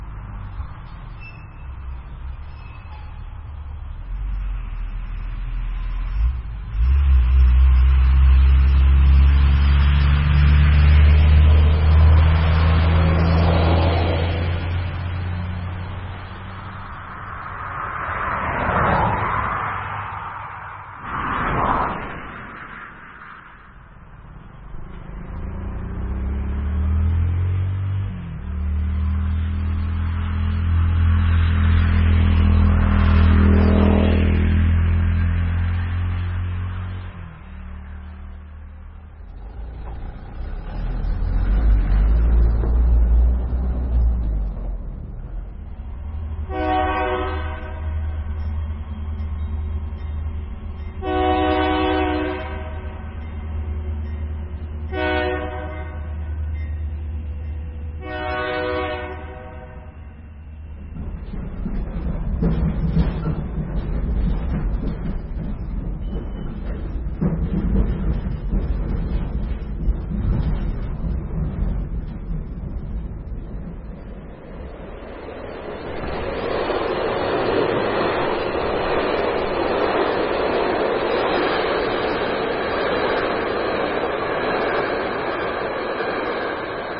音效-223种汽车皮卡柴油卡车火车赛车直升机飞机等运输工具
所有录音（通常都是可循环播放的）都是从远处执行的，或者表现出足够的细微，氛围和不引人注目，非常适合于各种背景设置和应用，给他们宁静，近乎自然的感觉。
多种多样的机动车辆：在道路，铁轨，水和空中，都具有内部氛围和外部记录。
运输，汽车，速度，路，快速，皮卡车，柴油卡车，停止，高速公路，环境，循环，交通，火车，铁路，发动机，旅客列车，室内，旅行，地下，机车，喇叭爆炸，回声，货运列车钟，拖拉，隆隆声，山腰，雷声，金属应变，桨划，水，闲置，河，船，巡航，螺旋桨，水下，摩托艇，轮船，通风，波浪拍打，甲板，直升机，飞机，喷射，机场，起飞，一级方程式，种族，赛车场